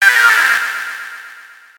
メール音やSMSの通知音。